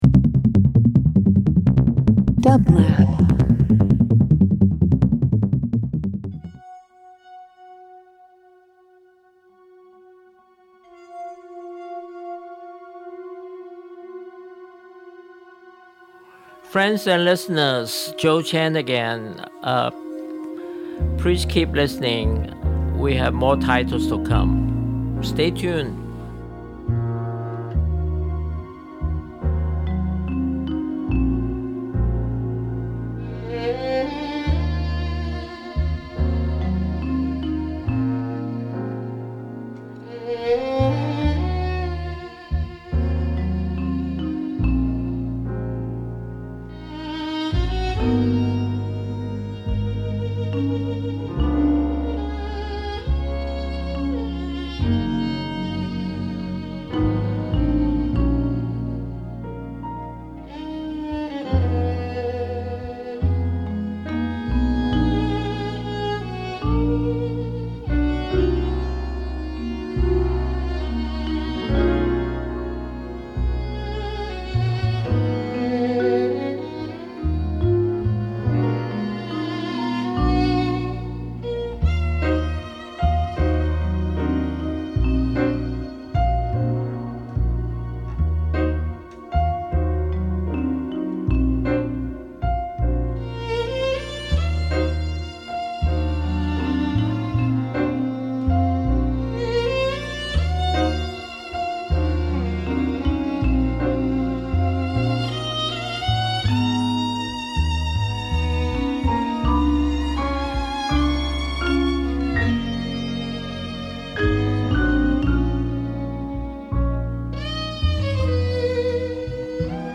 Alternative Classical Pop Soundtracks